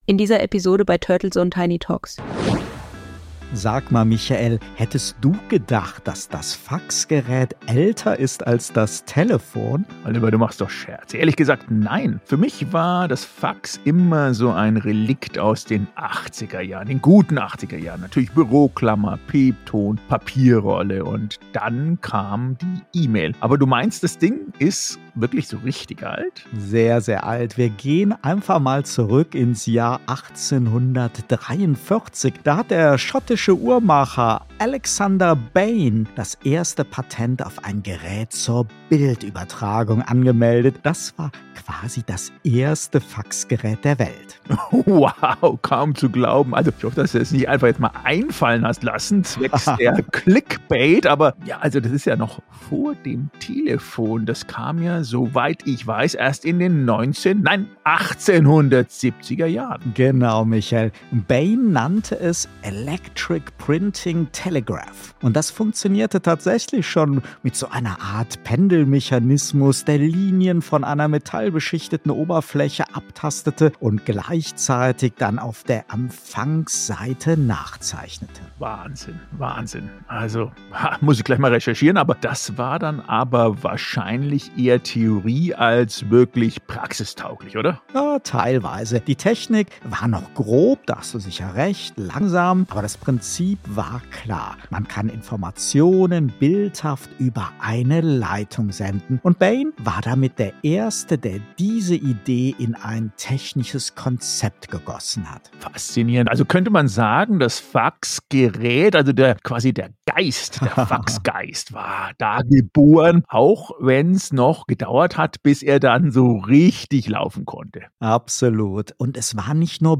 Nicht nur in Japan eine schöne Tradition. 35 spannende Podcast-Minuten mit einer musikalischen Liebeserklärung an ein technisches Fossil, dessen Geburtsstunde bis ins Jahr 1843 zurückreicht…